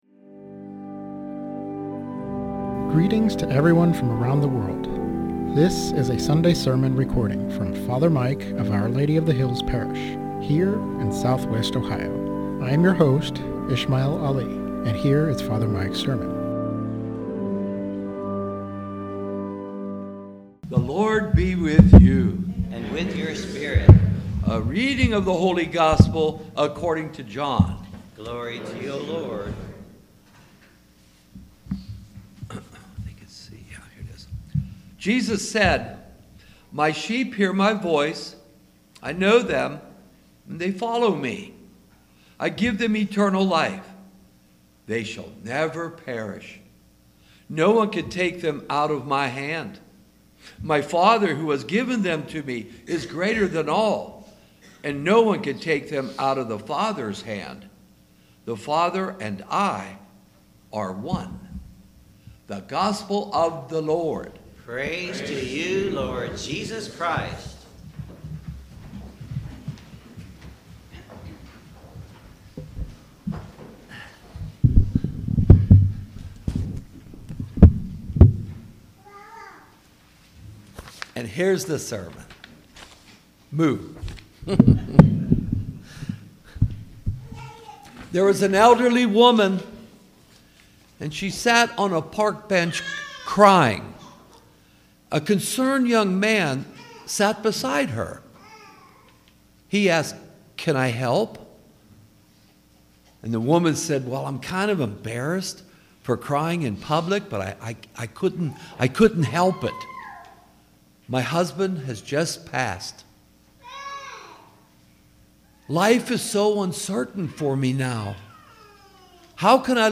Sermon on John 10:27-30 The Good Shepherd - Our Lady of the Hills - Church